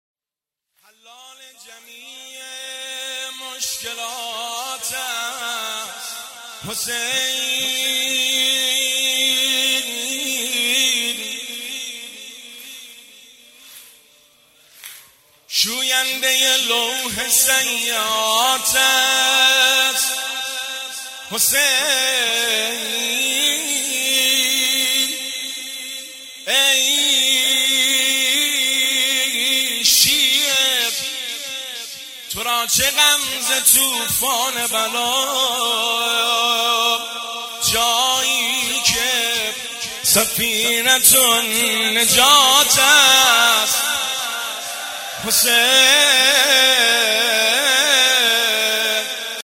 زمزمه شب دوم وفات حضرت معصومه (س) 1401
حسینیه چهارده معصوم شهر ری